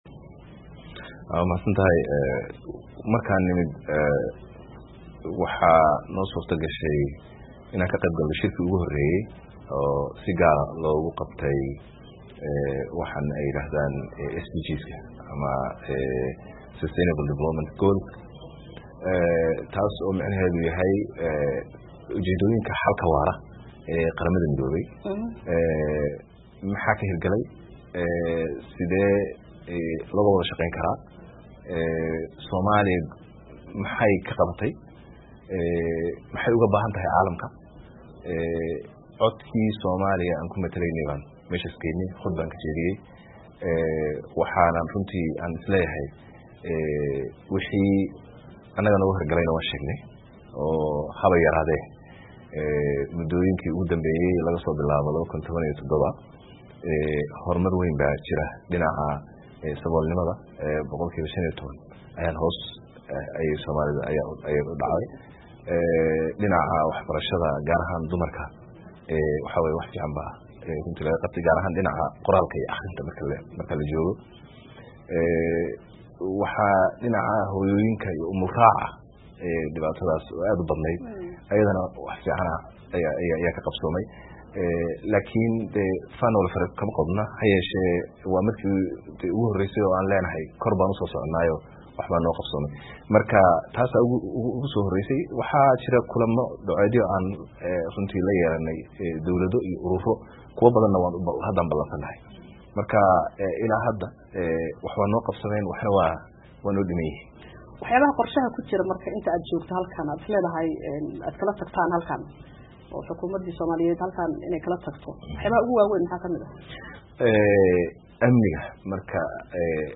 Wareysi: Ra'iisul Wasaare Xamza Cabdi Barre oo la hadlay VOA